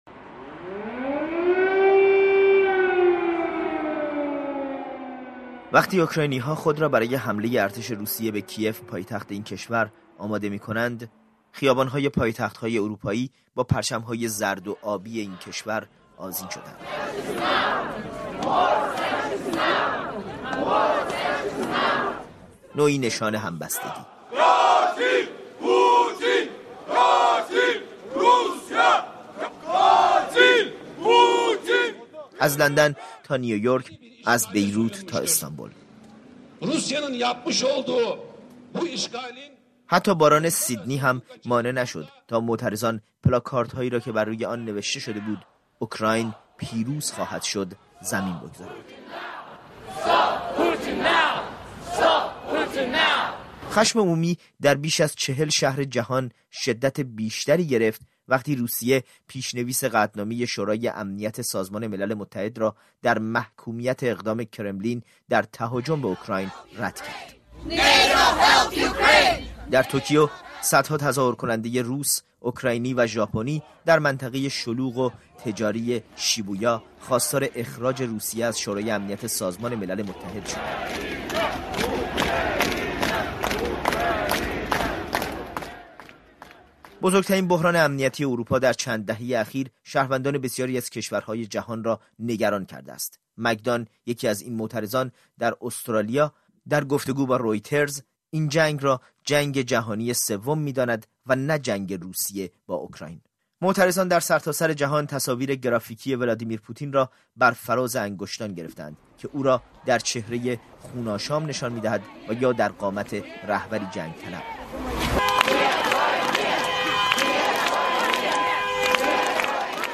خبرها و گزارش‌ها